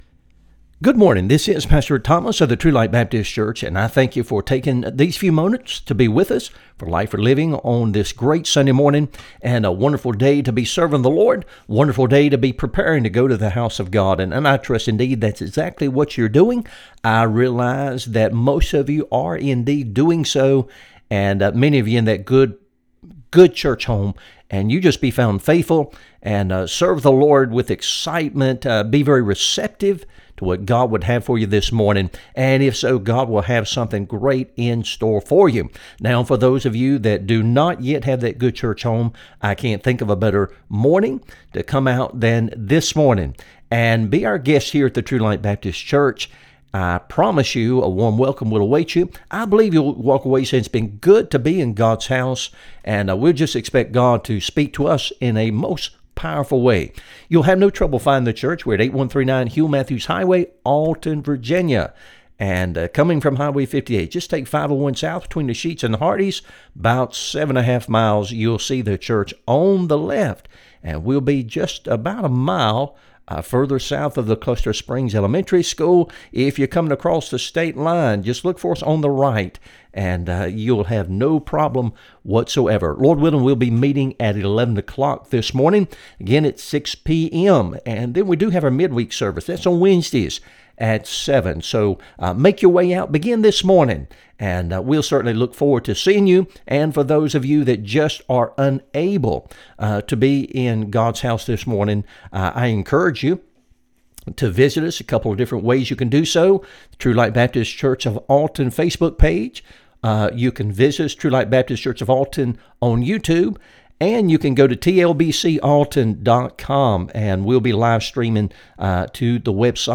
Light for Living Radio Broadcast